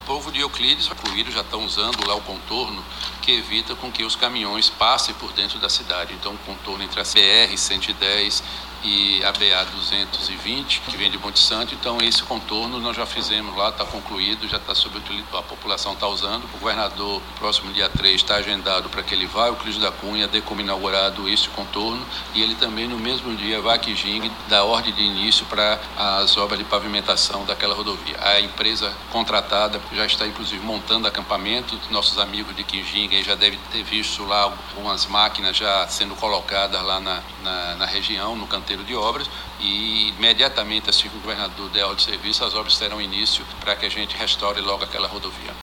Começam a “sair do papel” as obras de recuperação de rodovias no território do sisal; secretário de Infra estrutura comenta cada trecho (ouça)